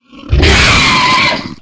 flesh_attack_3.ogg